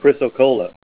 Name Pronunciation: Chrysocolla + Pronunciation Synonym: Bisbeeite Chrysocolla Image Images: Chrysocolla Comments: Botryoidal chrysocolla lining a vug.